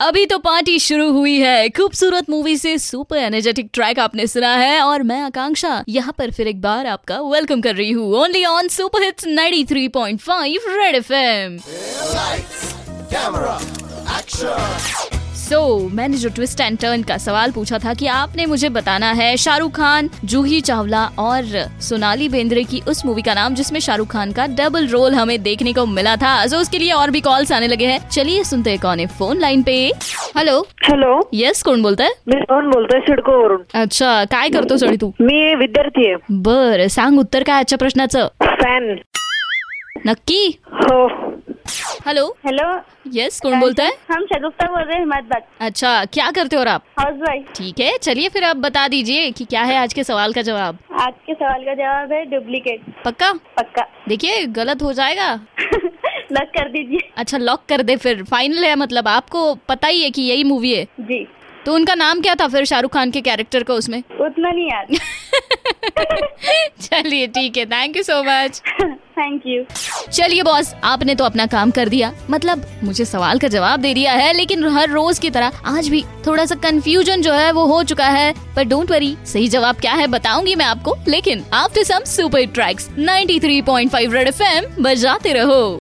interacting with listeners in Twist and turn.